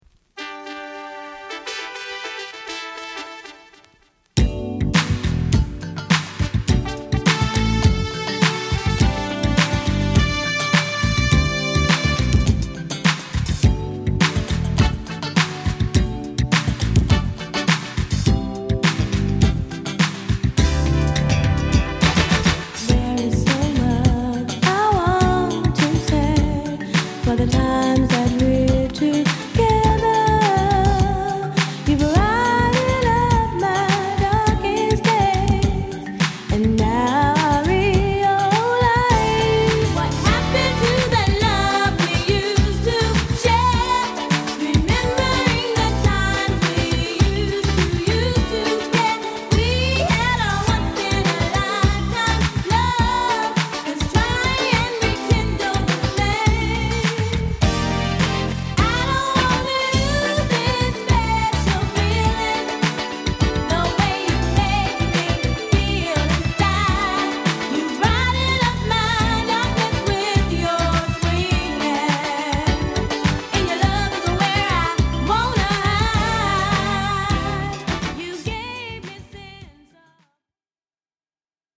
R&B／ソウル・シンガー
リラックスムード溢れるヴォーカルも抜群のメロディアスなソウル・ダンサー